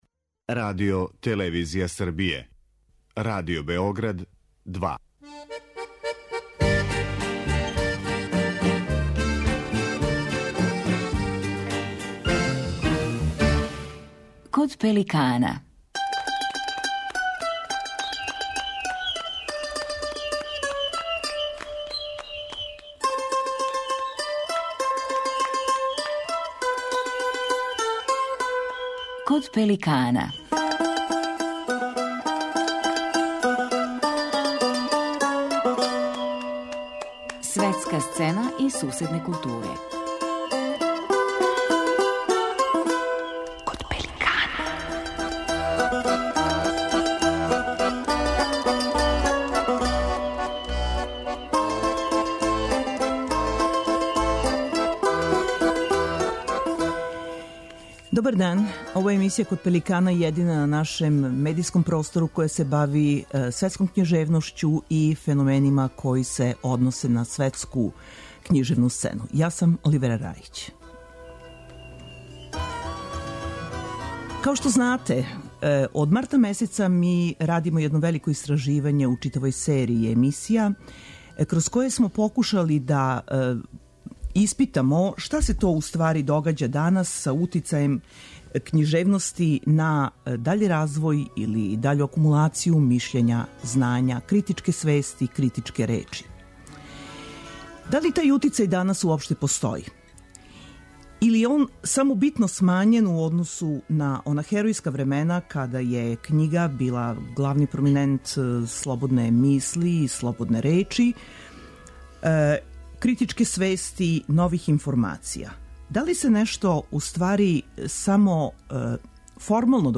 Вида Огњеновић, књижевница, драматург и председница српског ПЕН-а, гошћа је данашњег издања емисије 'Код пеликана'.
Разговором са Видом Огњеновић завршавамо вишемесечну серију истраживања о друштвеном значају књижевности данас.